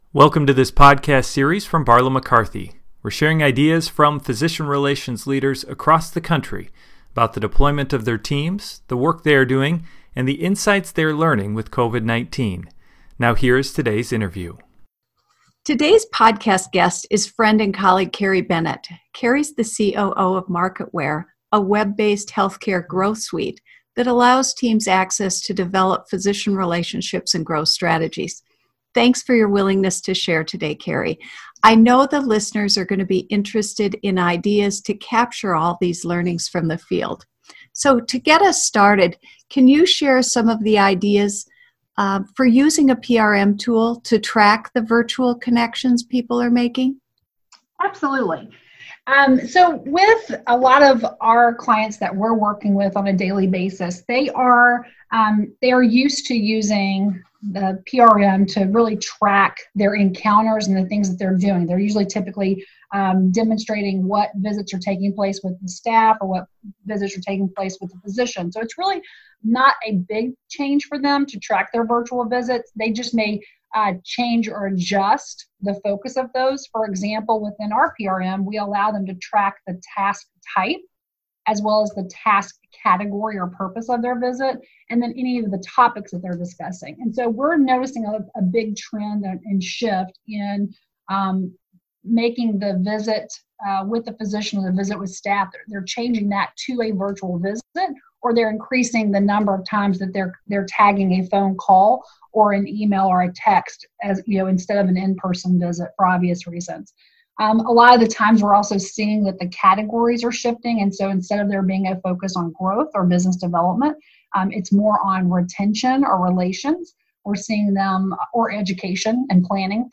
B/Mc Podcast: COVID-19 and Physician Relations – Leadership Interview #5